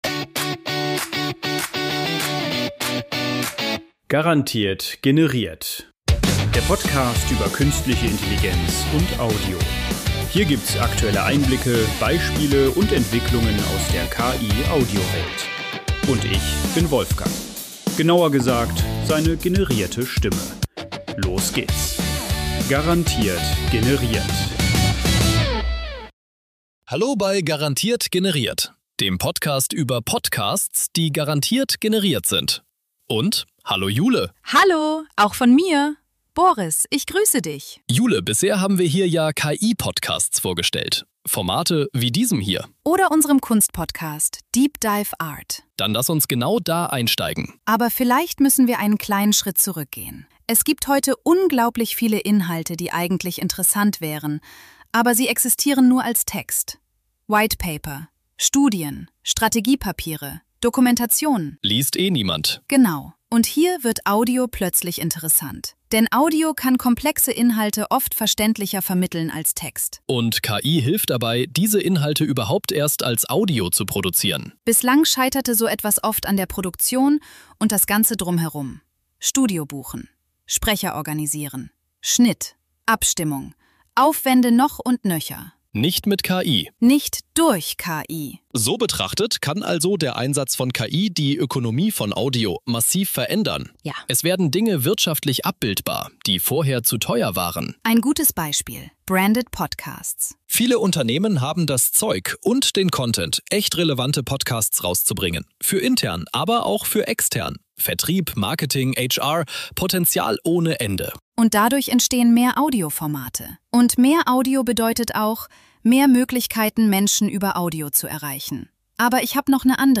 "Garantiert generiert" sind alle Inhalte, Töne, Dialoge, Sounds